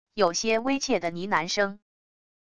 有些微怯的呢喃声wav音频